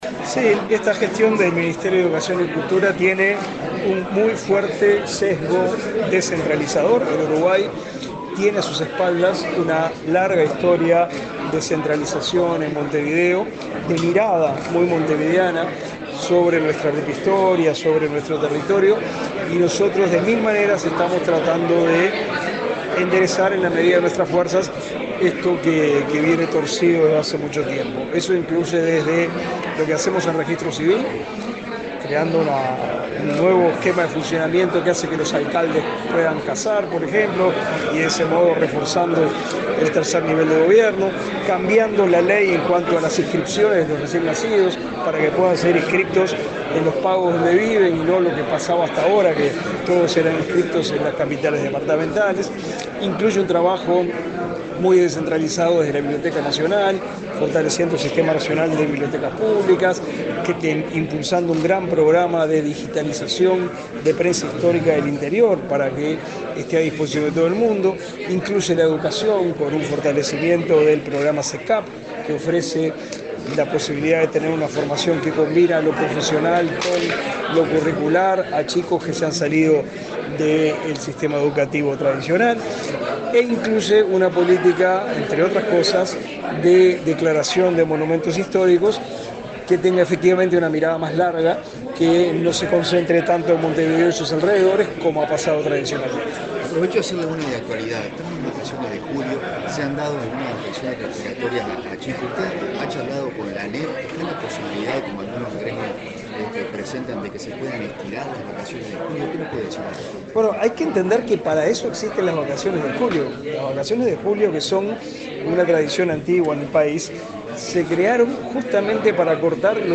Declaraciones del ministro de Educación y Cultura, Pablo da Silveira
Declaraciones del ministro de Educación y Cultura, Pablo da Silveira 10/07/2024 Compartir Facebook X Copiar enlace WhatsApp LinkedIn El ministro de Educación y Cultura, Pablo da Silveira, dialogó con la prensa en Colonia, luego de participar en el acto en el que se declaró Monumento Histórico Nacional al hallazgo arqueológico del molino hidráulico Náper de Lencastre.